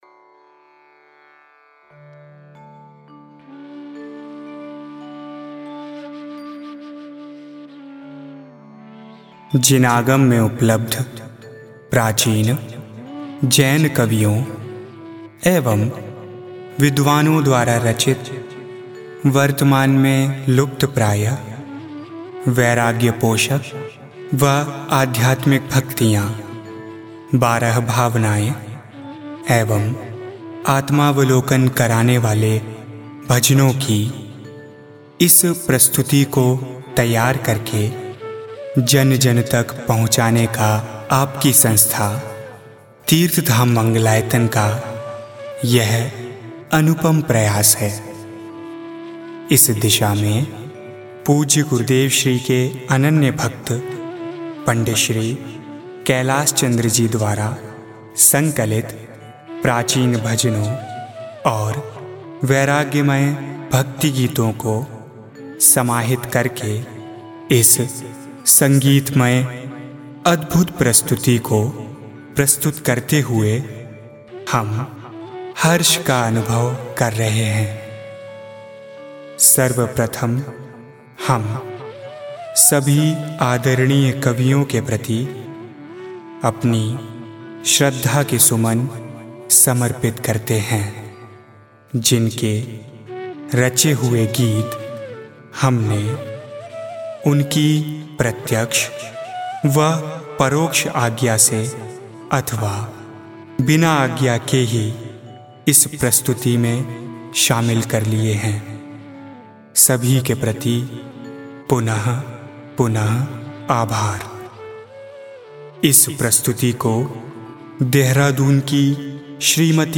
Audio Bhajan